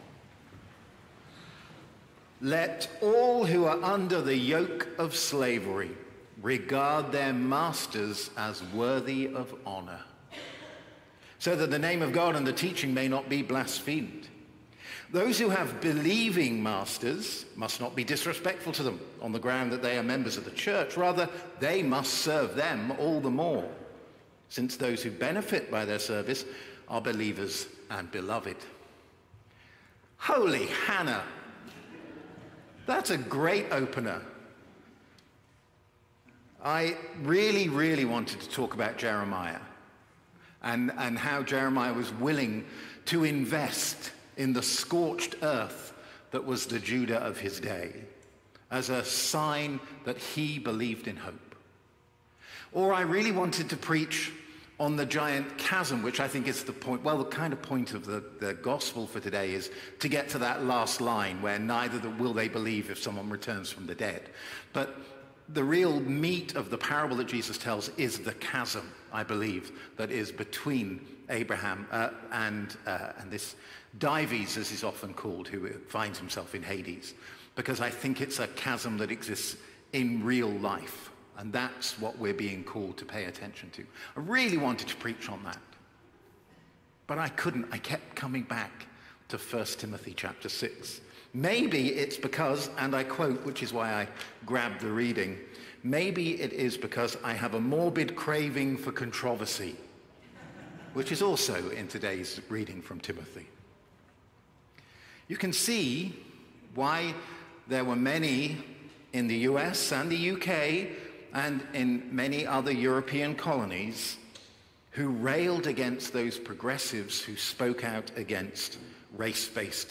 Sermons | St. John the Divine Anglican Church